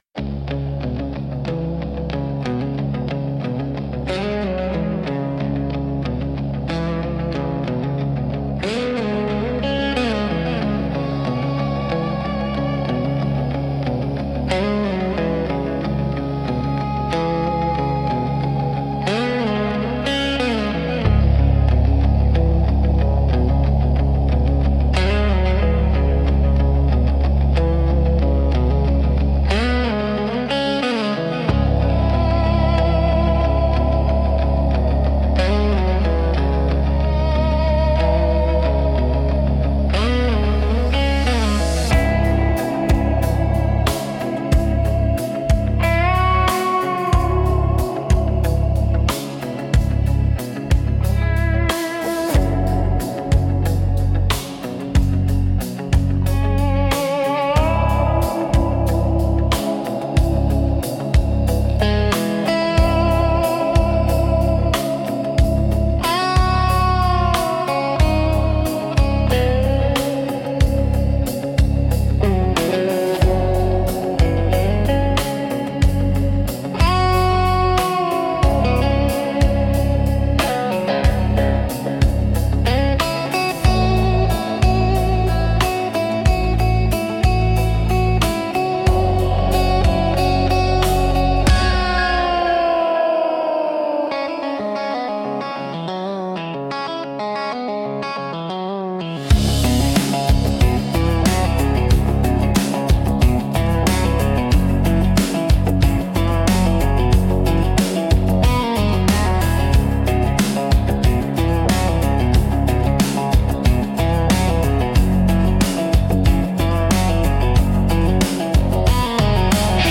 Instrumental - Mile Marker Blues 4.09